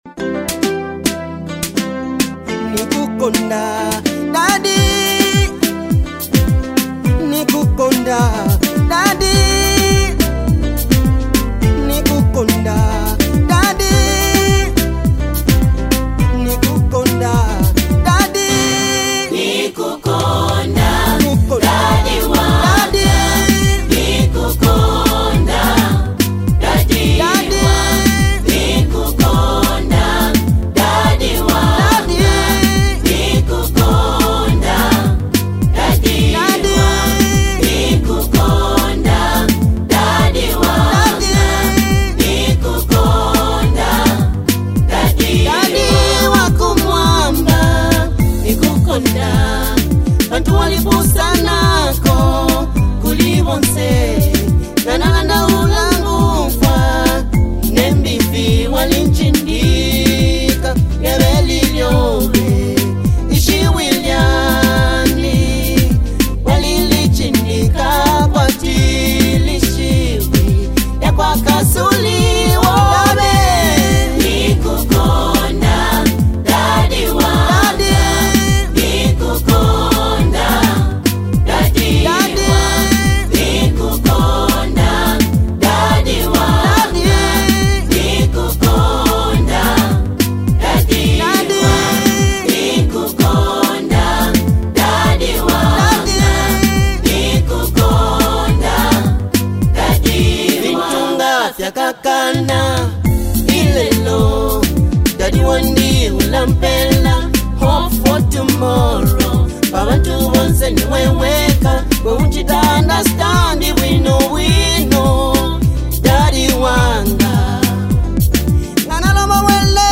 gospel
soul-stirring track